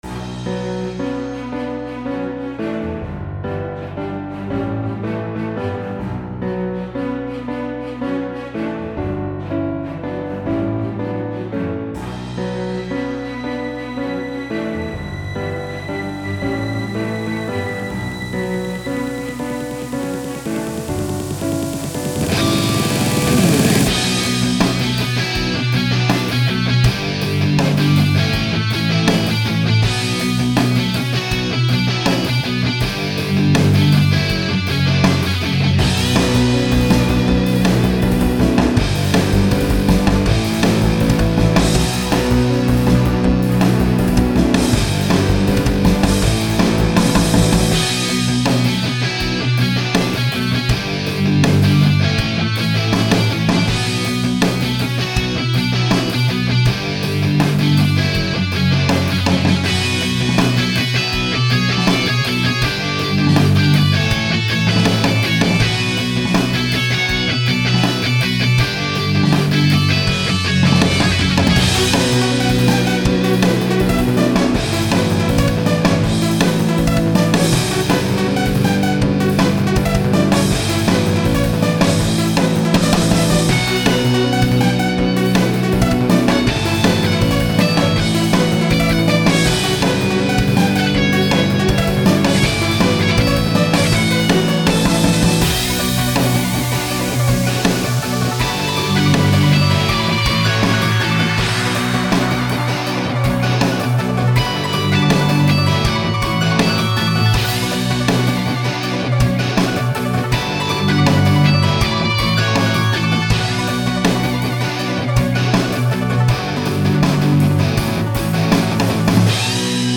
This piece blends symphonic rock with electronic elements to create an epic, mechanical theme.
orchestra mor i and ii percussion strings brass electric guitars refx nexus synths epic tense robotic electronic symphonic rock raum reverb
Sounds like a boss fight!
It's definitely overdriven!